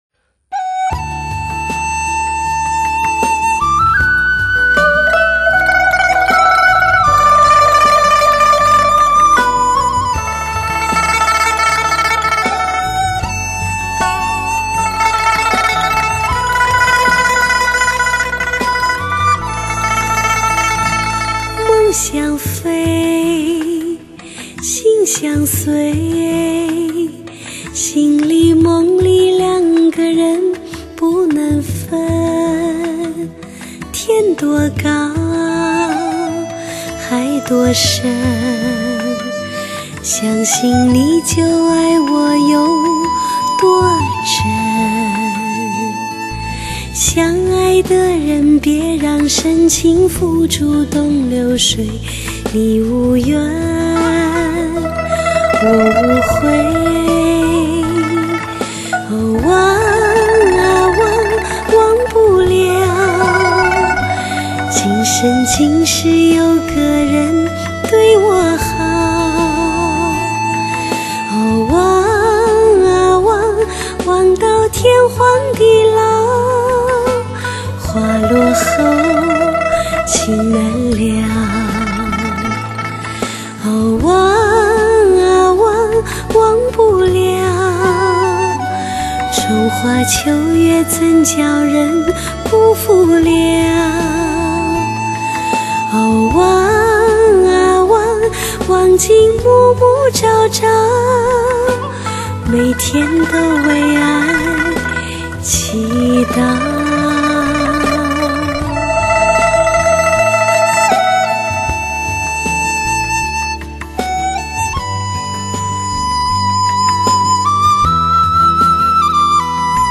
国际音响协会多声道音乐录音典范
强势高清解码还原真声 带来超乎想象的震撼级高临场感